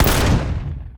weapon_sniper_004.wav